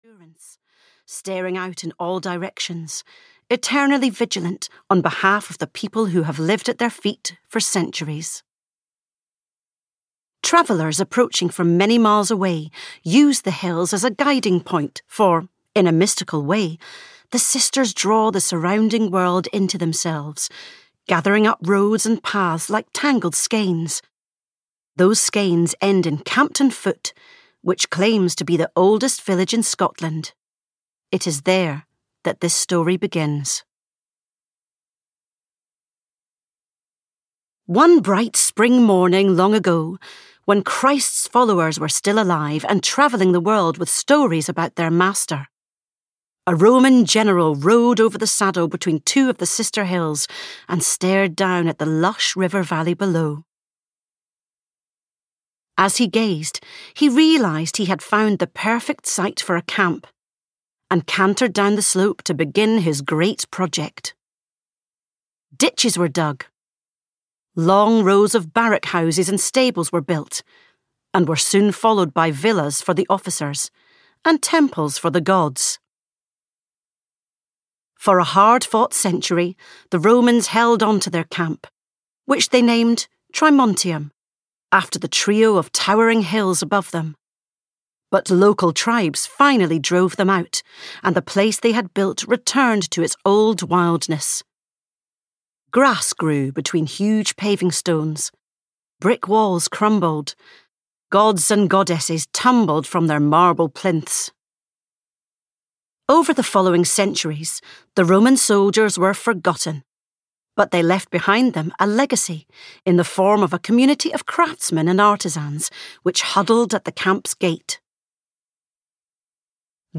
A Bridge in Time: A moving Scottish historical saga (EN) audiokniha
Ukázka z knihy